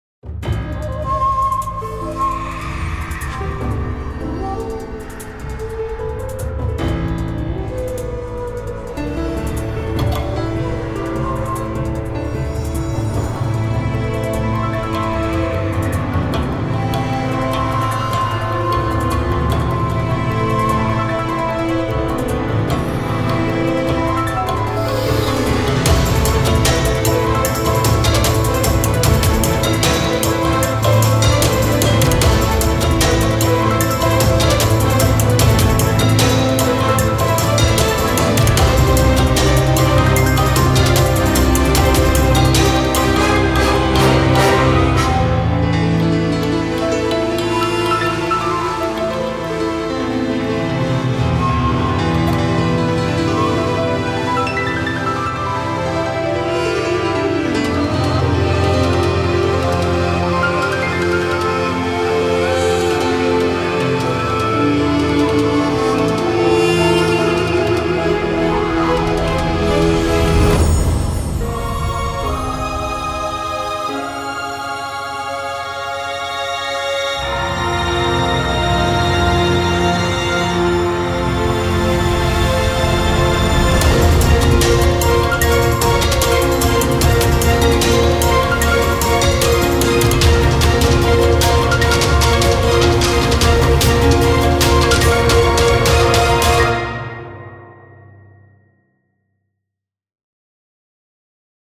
三味線・笛など、和楽器を使用。起承転結のある激しい曲